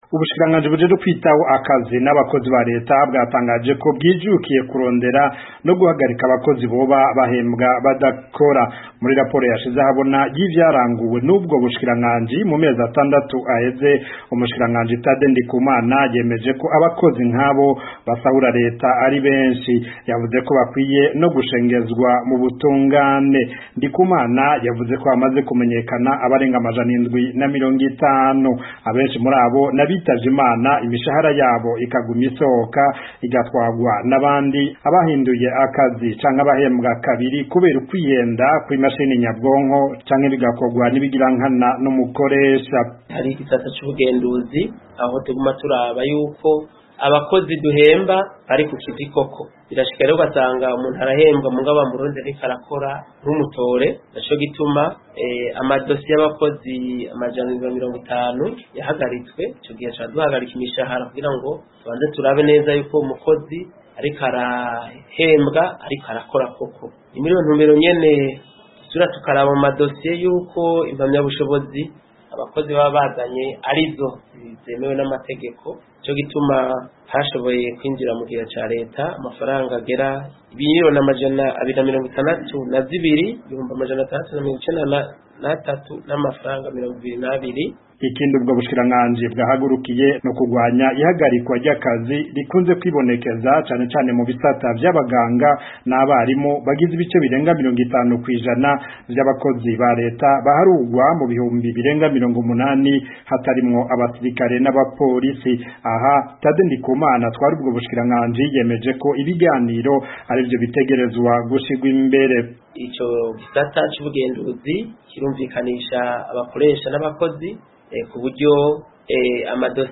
yabikurikiranye ategura inkuru mushobora kumva mu ijwi rye hano hepfo